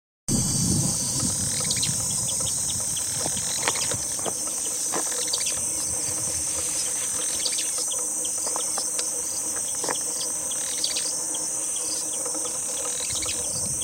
Barullero (Euscarthmus meloryphus)
Nombre en inglés: Fulvous-crowned Scrub Tyrant
Condición: Silvestre
Certeza: Vocalización Grabada
barullero.mp3